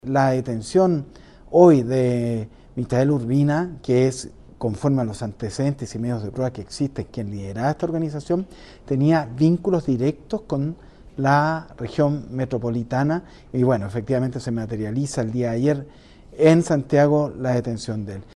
Para el general Jaime Velasco Bahamondez, director de Control Drogas e Investigación Criminal de Carabineros, la detención del líder representa un golpe significativo contra el crimen organizado en O’Higgins.